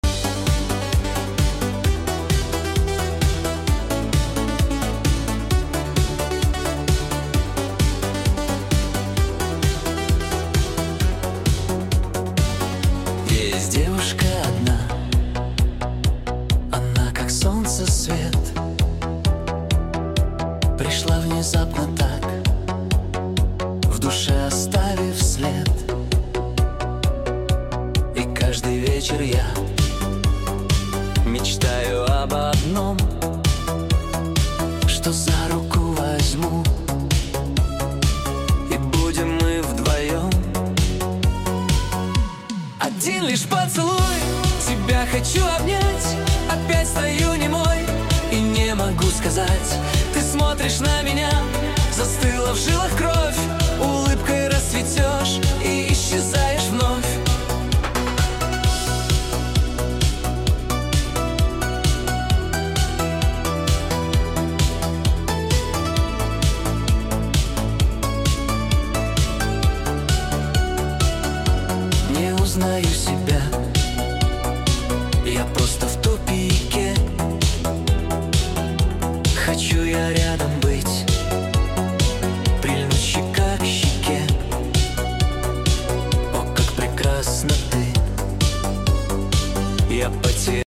Качество: 128 kbps, stereo